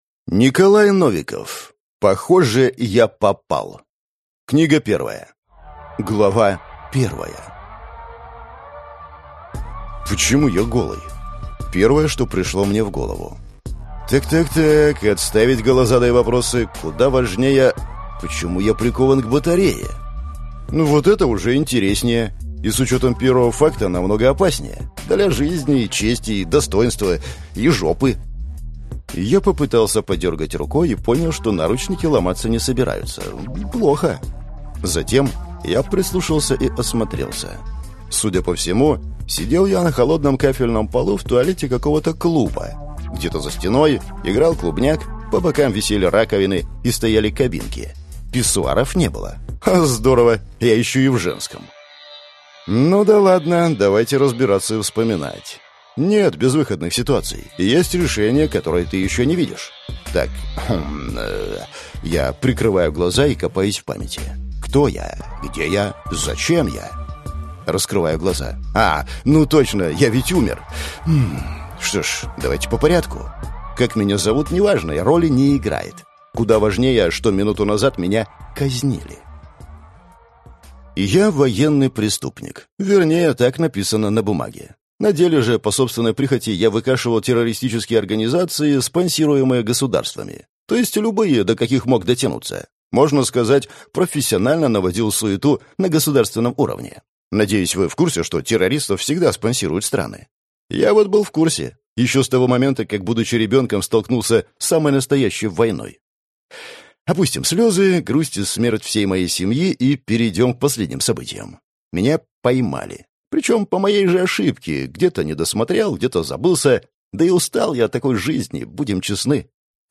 Аудиокнига Похоже, я попал. Книга 1 | Библиотека аудиокниг